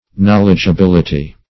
knowledgeability - definition of knowledgeability - synonyms, pronunciation, spelling from Free Dictionary